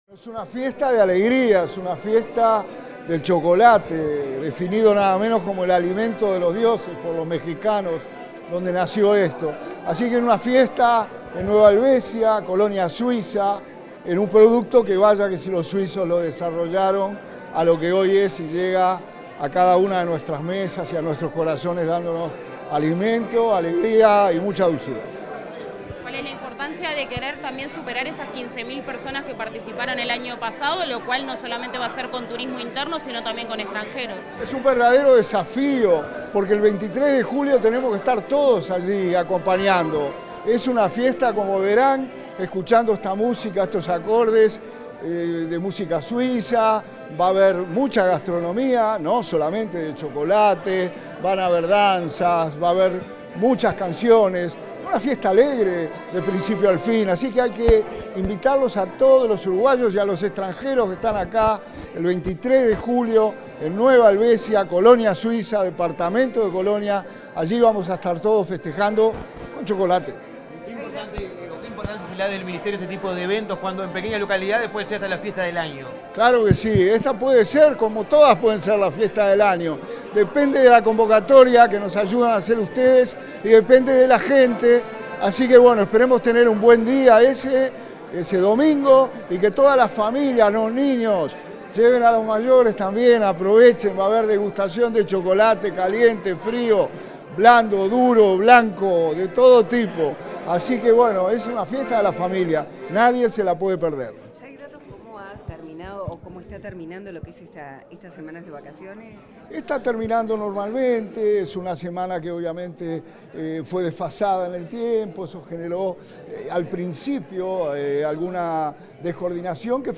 Declaraciones del subsecretario de Turismo, Remo Monzeglio
El subsecretario de Turismo, Remo Monzeglio, dialogó con la prensa luego de participar en el lanzamiento de la Fiesta Nacional del Chocolate, que se